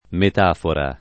[ met # fora ]